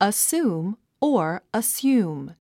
発音
əsúːm　アスーム